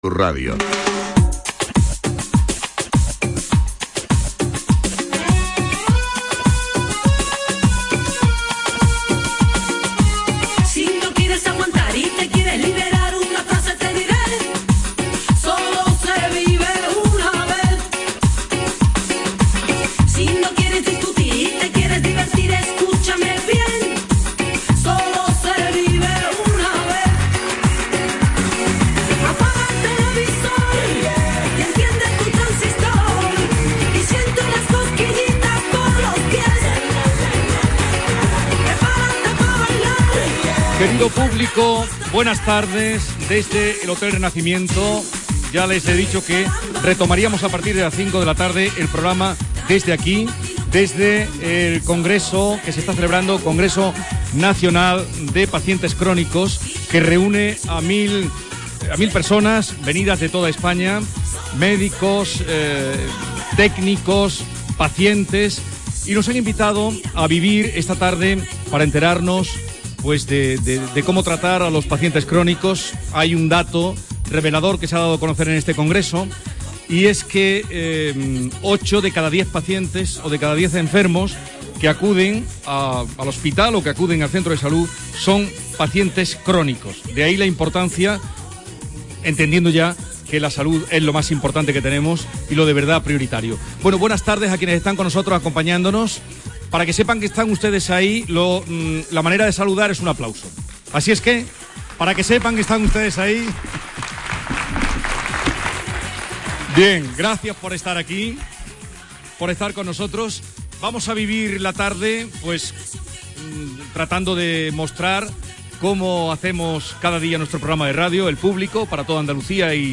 Programa El Público de Canal Sur Radio retransmitido desde la sede del VI Congreso Nacional de Atención Sanitaria al Paciente Crónico y I Conferencia Nacional de Pacientes Activos celebrada en Sevilla el 28 de marzo de 2014, con participación de pacientes y profesionales de la EASP.